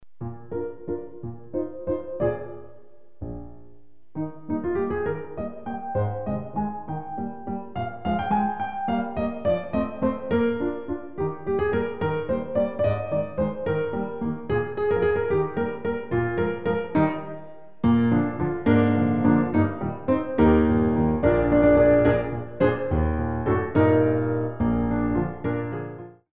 Light Waltz